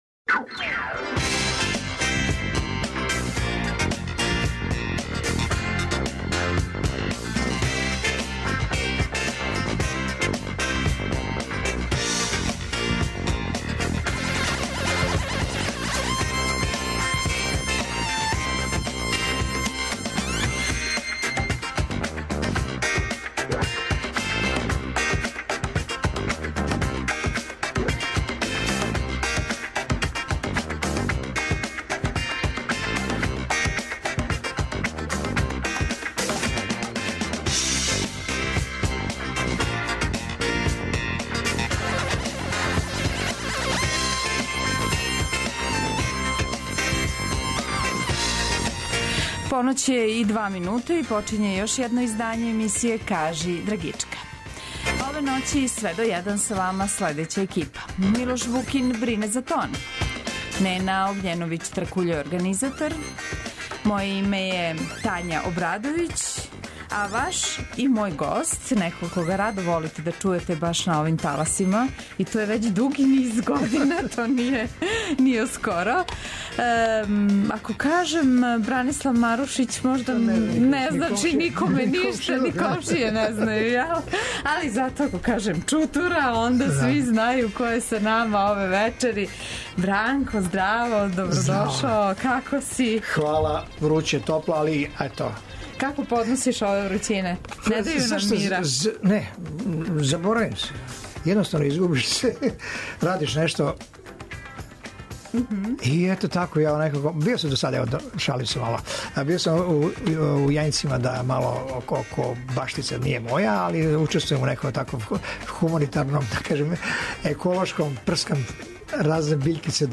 Свако вече, од поноћи на Двестадвојци у емисији Кажи драгичка гост изненађења! Музички гост се, у сат времена програма, представља слушаоцима својим ауторским музичким стваралаштвом, као и музичким нумерама других аутора и извођача које су по њему значајне и које вам препоручују да чујете.